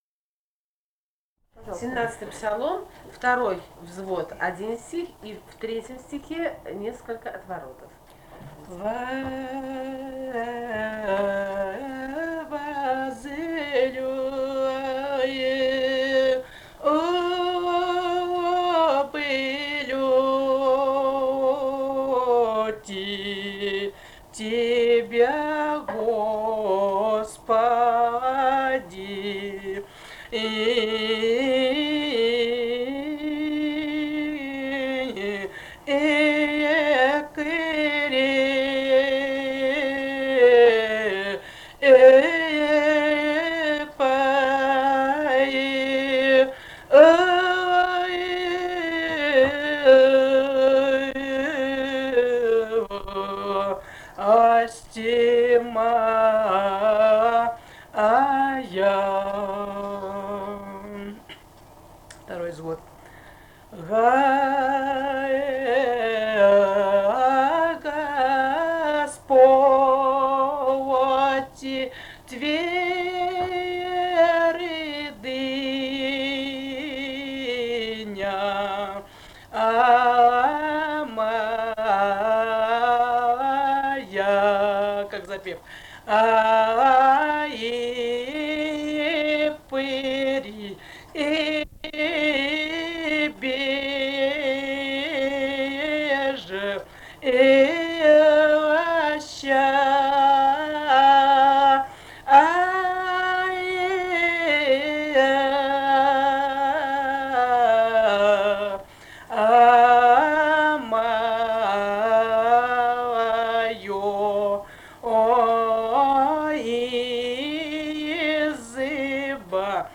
полевые материалы
«Возлюблю Тебя, Господи». Псалом 17 (взводы и отвороты).
Грузия, г. Тбилиси, 1971 г. И1311-15